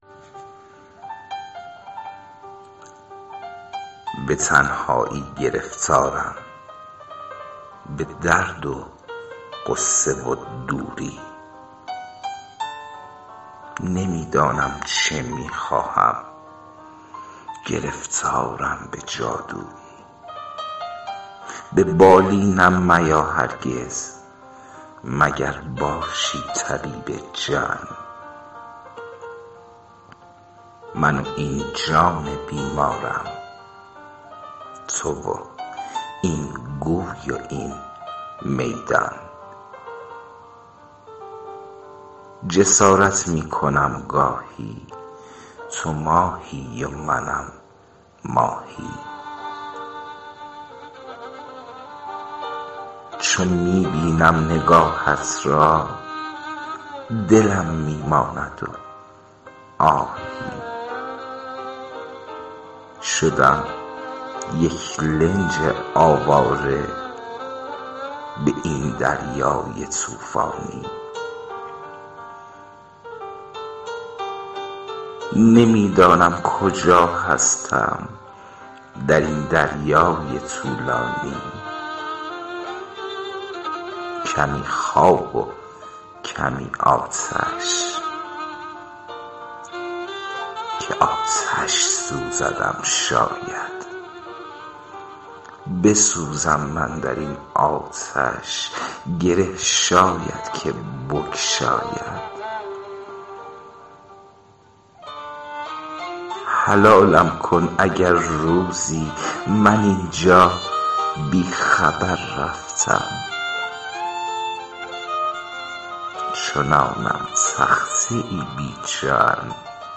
دکلمه سرگردان